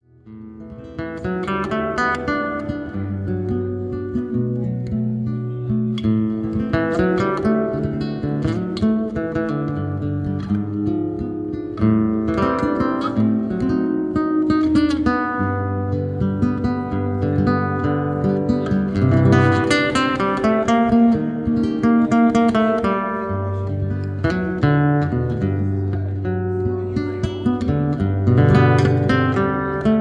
Live in Little Tokyo
Soothing and Relaxing Guitar Music